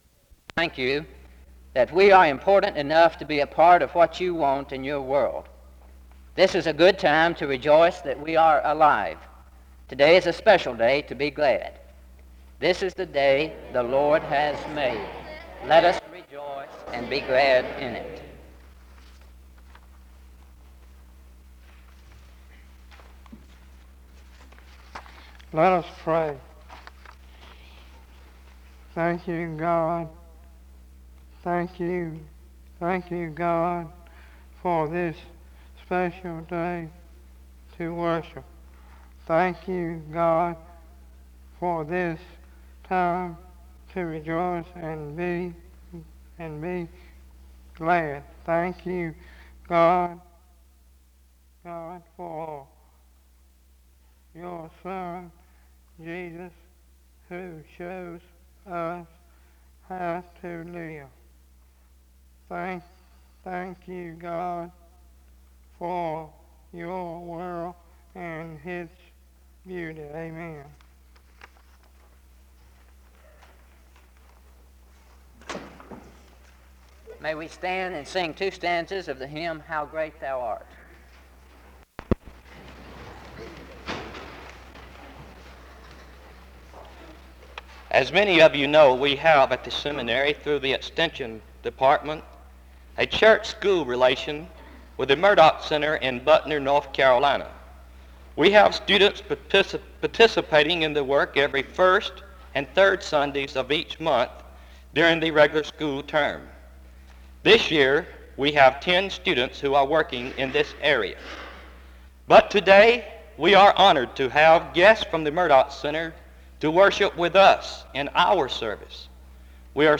SEBTS Chapel - Murdoch Center November 5, 1969
SEBTS Chapel and Special Event Recordings